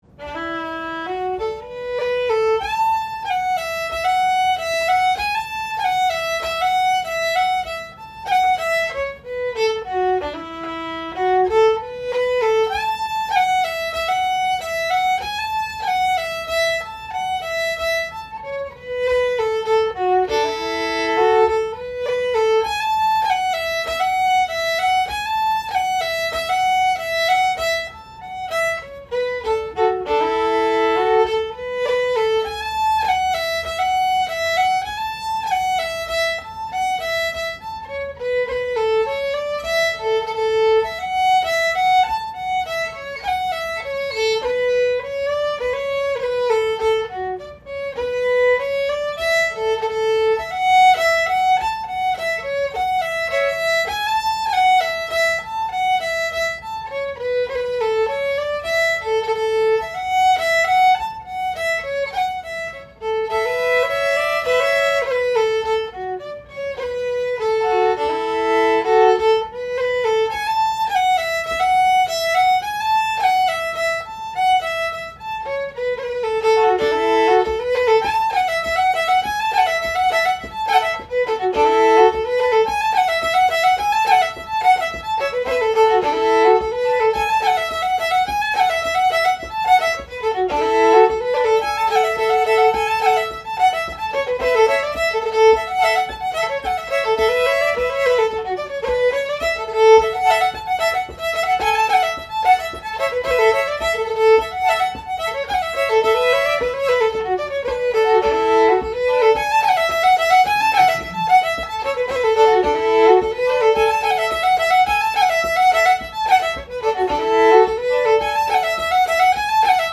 Sessions are open to all instruments and levels, but generally focus on the melody.
Composer Ewen Henderson Type Reel Key A Recordings